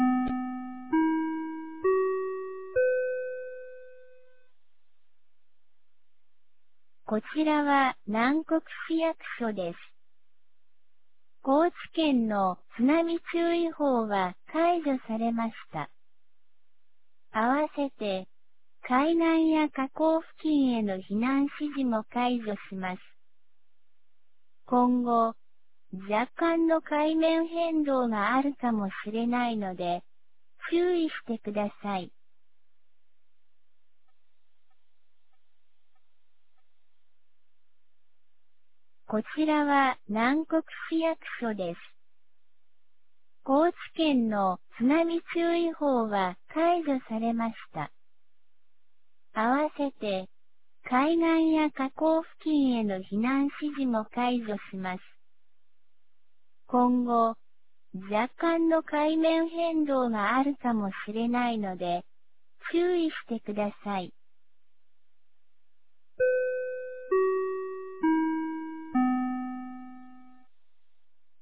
南国市放送内容
2025年01月14日 00時02分に、南国市より放送がありました。